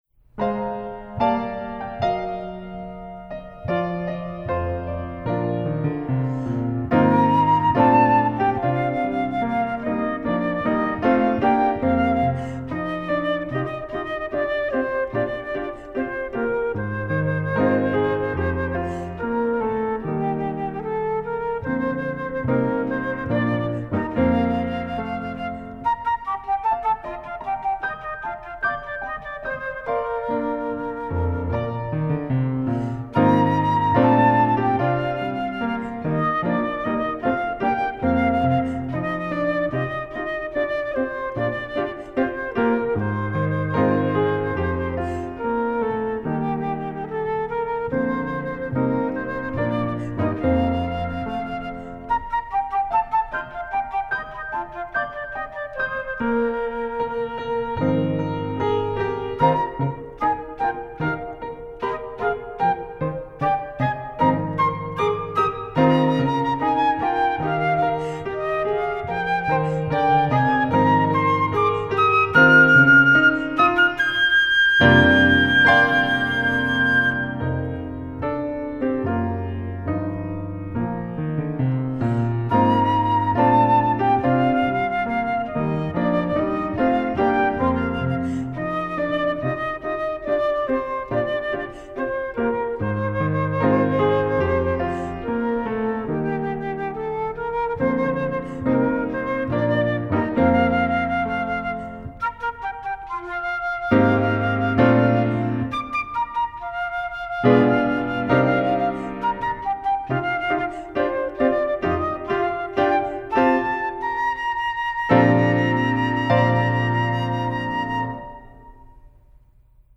Style: Lyrical and Fun
Flute and Piano
The recording, done at St. Lukes Lutheran (Oveido, Florida)